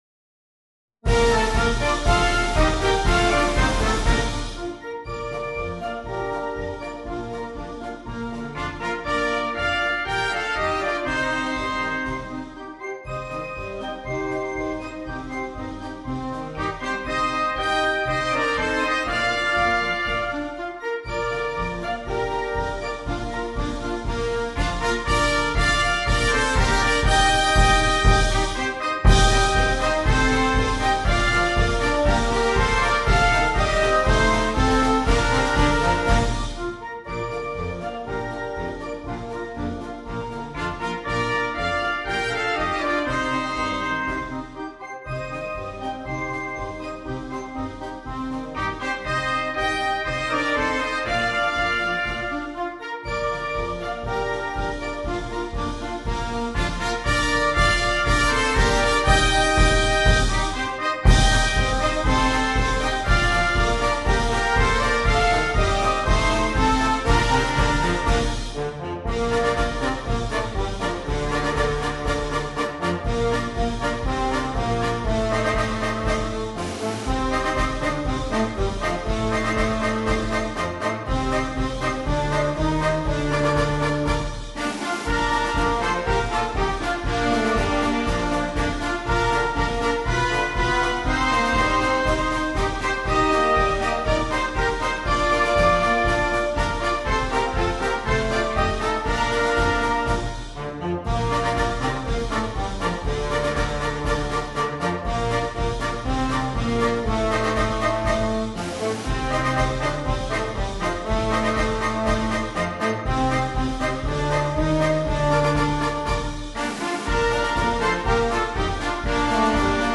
Marcia brillante